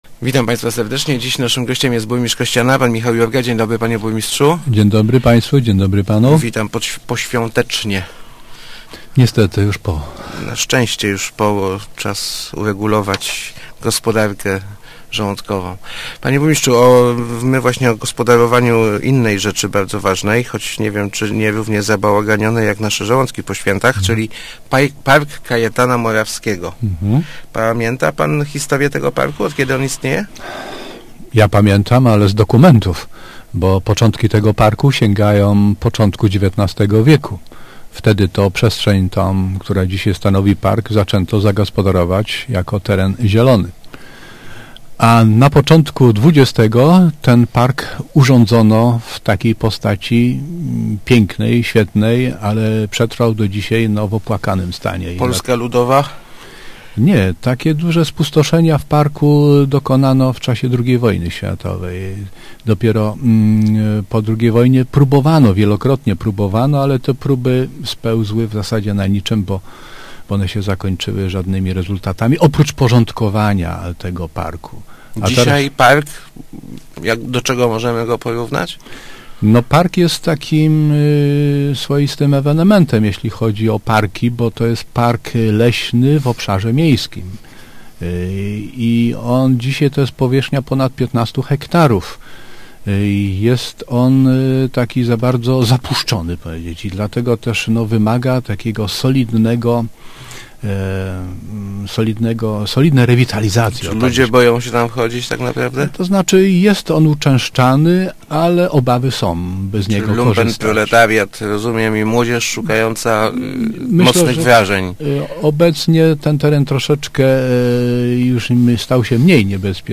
Chcemy przywrócić mu dawną, przedwojenną świetność – mówił w Rozmowach Elki burmistrz Michał Jurga.